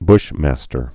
bush·mas·ter
(bshmăstər)